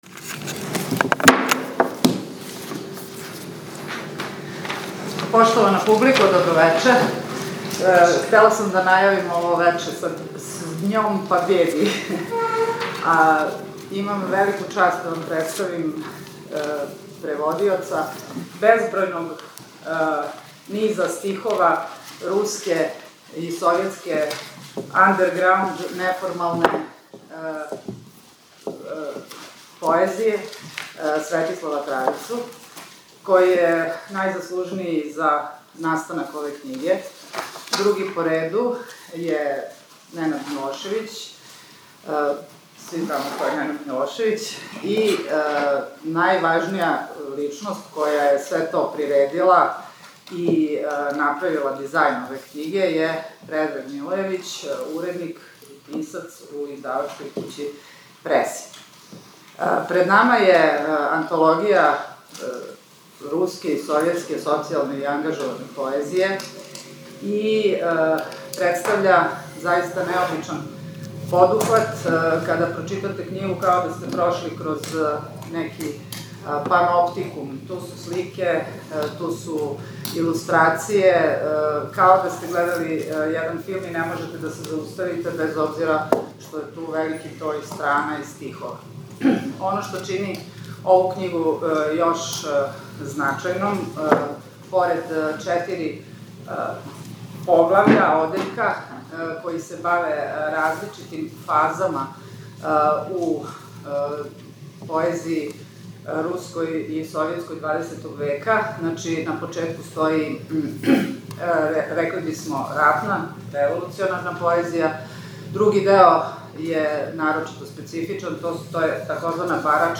Четвртак 9. мај 2024. у 18 сати Француска 7, Београд